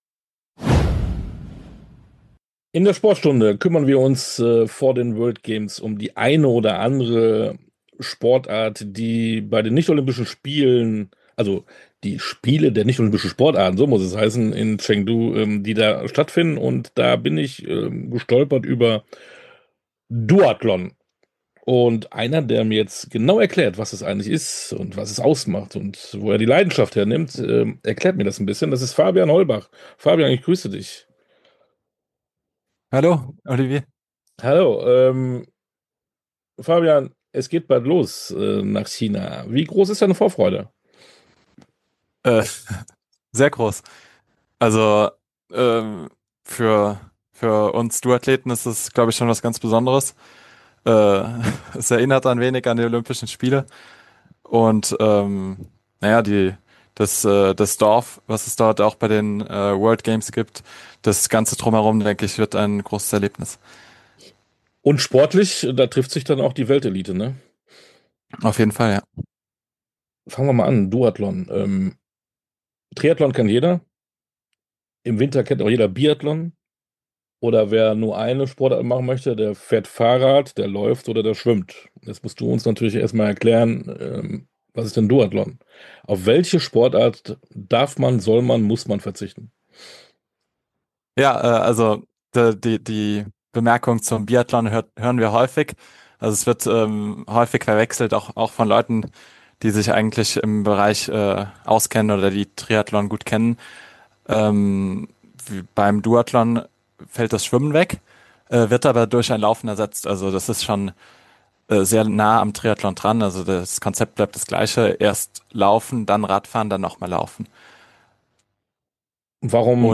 Tauchen Sie ab in die Sportstunde ungeungeschnittentinterviews in ihrer authentischen, ungeschnittenen Langfassung. Diese tiefgründigen Sportstunde Interviews liefern Ihnen nicht nur Fakten, sondern auch einzigartige Einblicke in die Welt des Sports.